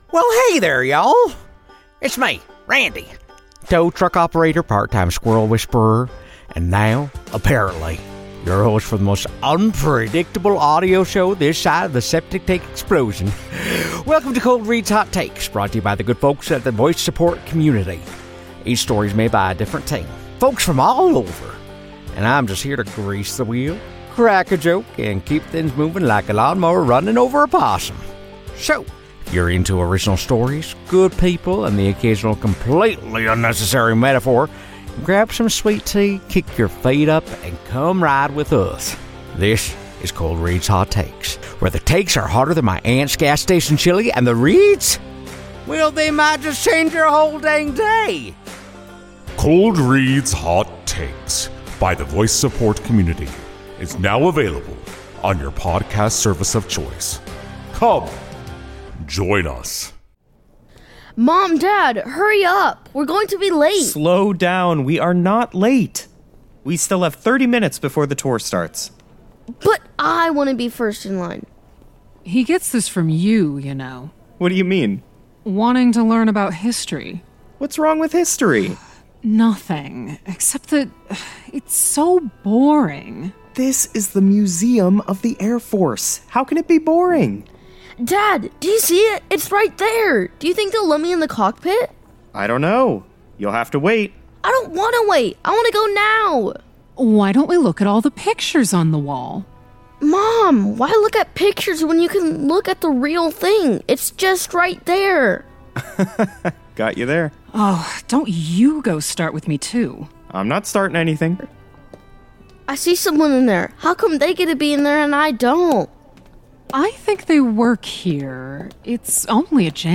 Welcome to Tales from the Janitor, a spine-chilling plunge into Ohio’s most unsettling urban legends, rust-belt folklore, and cornfield-haunted mysteries… all told through the mop-wielding perspective of your eerie (and oddly well-informed) custodial guide.
Every story is delivered with rich atmosphere, creeping dread, and just enough dry humor to keep you nervously chuckling as the wind rattles through the cornfields outside.